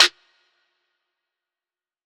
SNARE - DENVER.wav